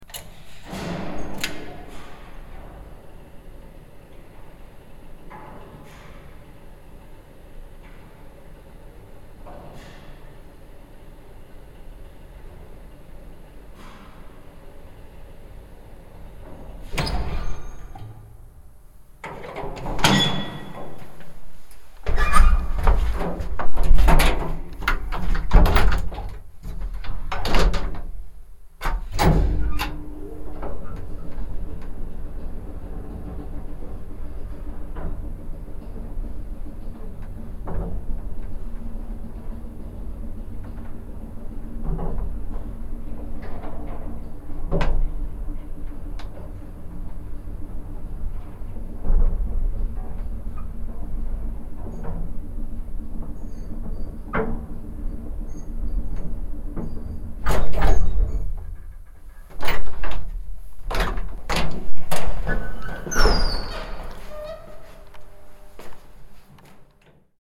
Sound Effects / Street Sounds 20 Feb, 2026 Elevator Journey Mechanical Sound Effect – Button Click, Doors, Motor Hum, Thud Read more & Download...
Elevator-journey-mechanical-sound-effect-button-click-doors-motor-hum-thud.mp3